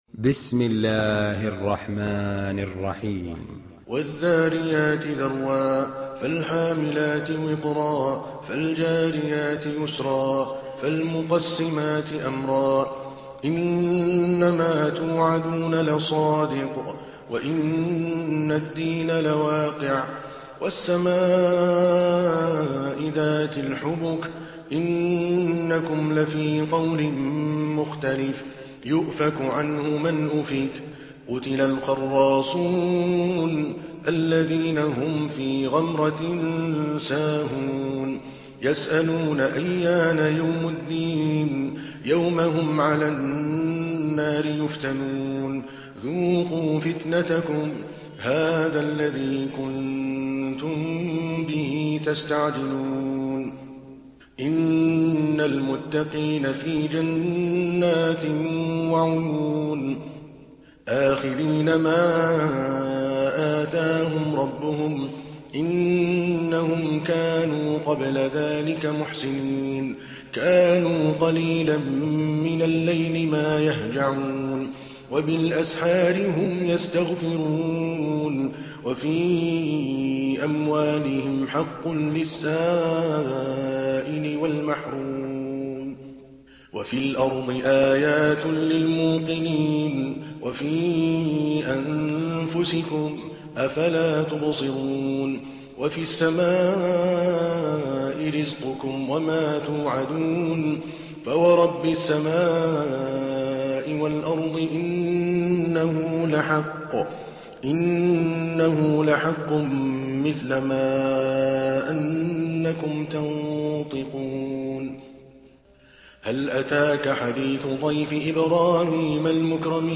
Surah Ad-Dariyat MP3 by Adel Al Kalbani in Hafs An Asim narration.
Murattal Hafs An Asim